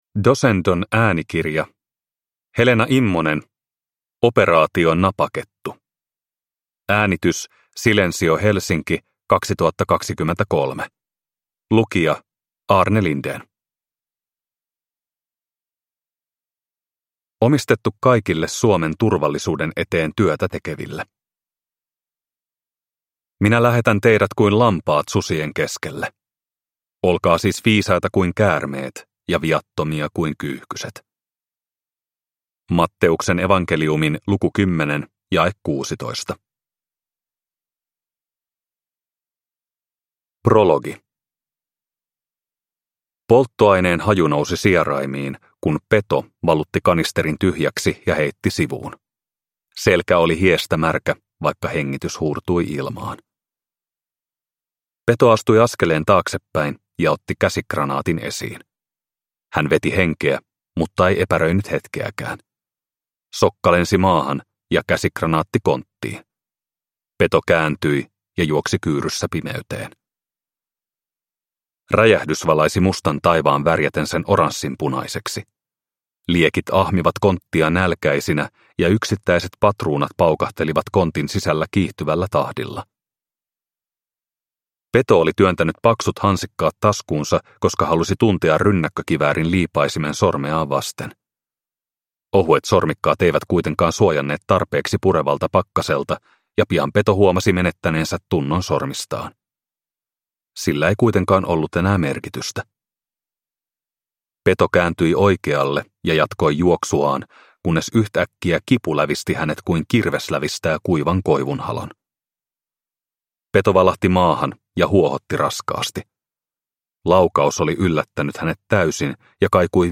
Operaatio Napakettu – Ljudbok – Laddas ner